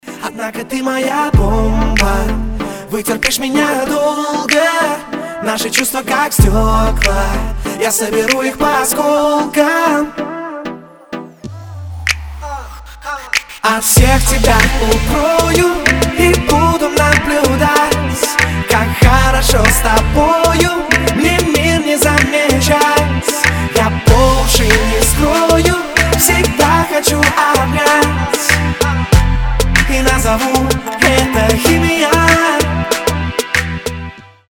• Качество: 320, Stereo
поп
мужской вокал
dance
романтические